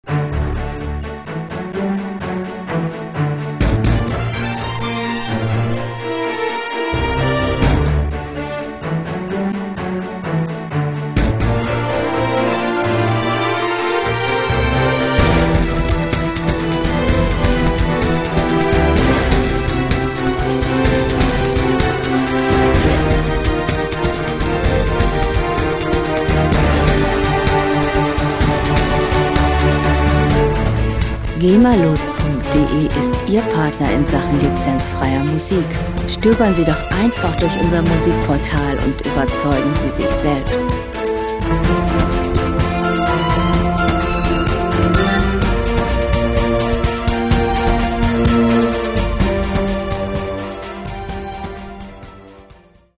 lizenzfreie Werbemusik für Imagefilme
Musikstil: Soundtrack
Tempo: 127 bpm
Tonart: A-Dur
Charakter: verhängnisvoll, zielstrebig
Instrumentierung: Orchester, Percussions